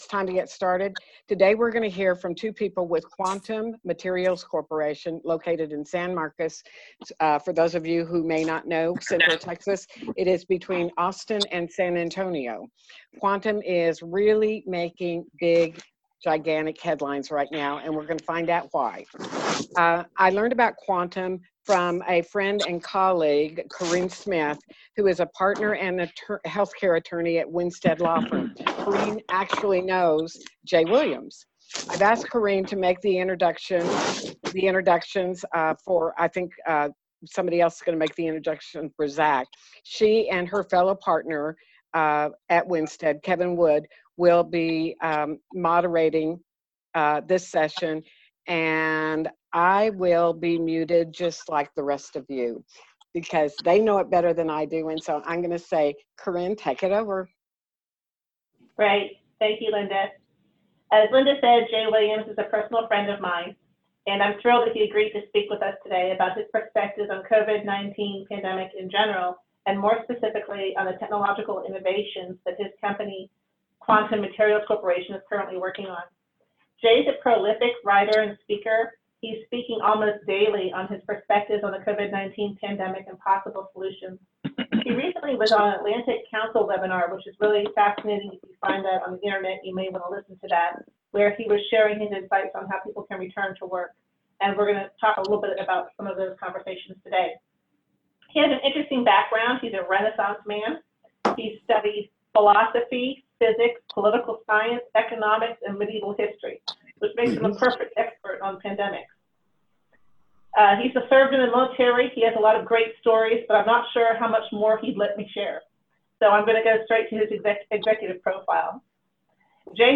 Online Conference with Quantum Materials